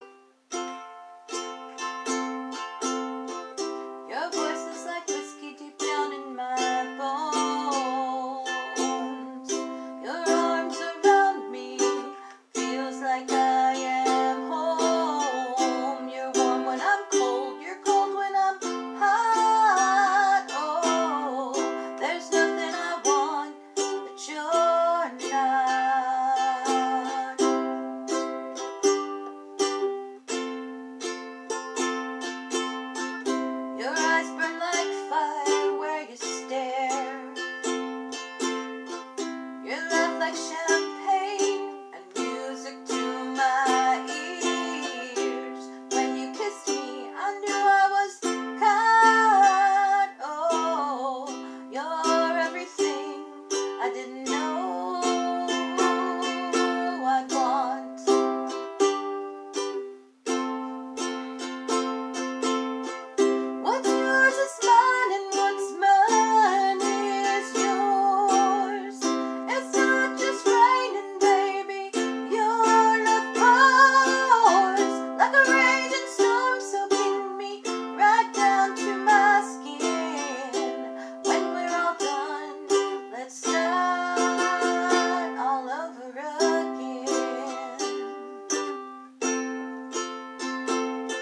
The time limit for audio makes it difficult to include an entire song from start to finish... so I try to trim to the most important parts of the song for getting the melody and a good idea of how the whole song comes together.
Your song cut off before the third verse, but I loved it.